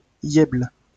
Yèbles (French pronunciation: [jɛbl]